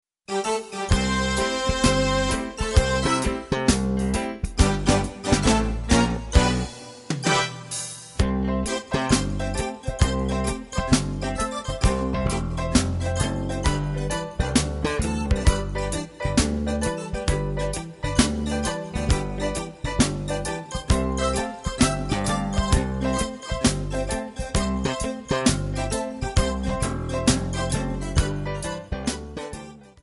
MPEG 1 Layer 3 (Stereo)
Backing track Karaoke
Pop, Oldies, 1950s